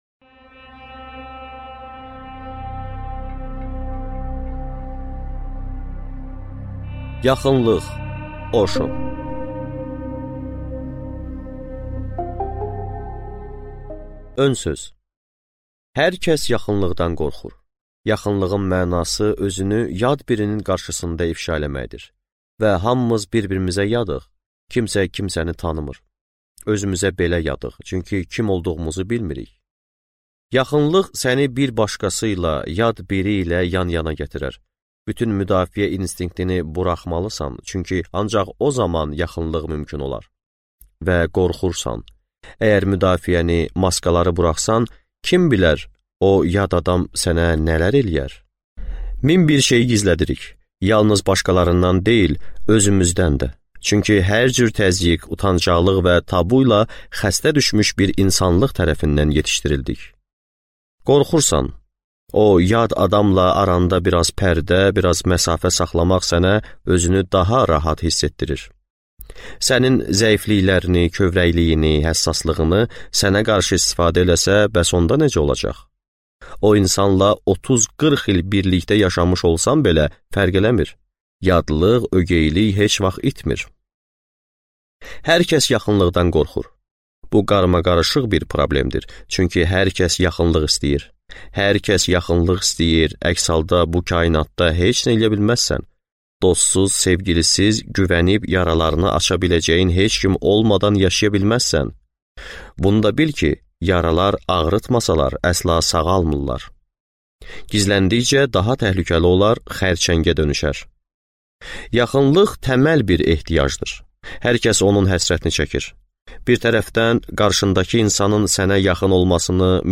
Аудиокнига Yaxınlıq | Библиотека аудиокниг